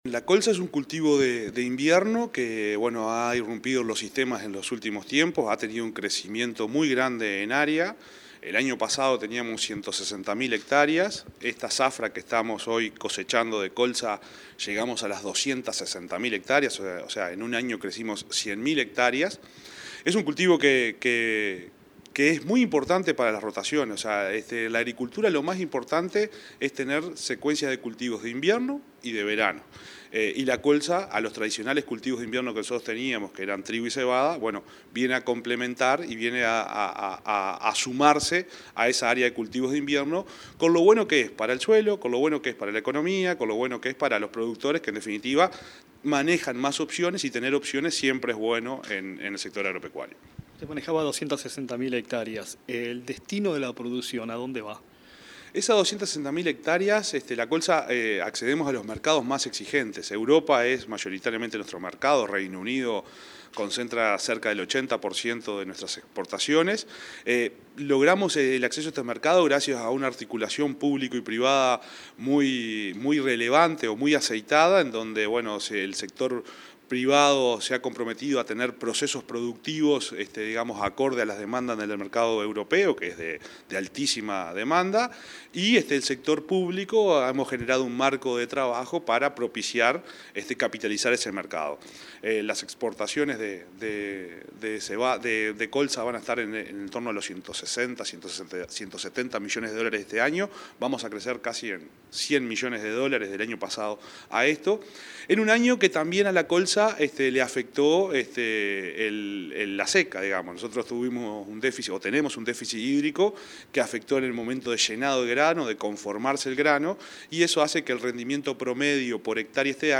Entrevista al subsecretario de Ganadería, Juan Ignacio Buffa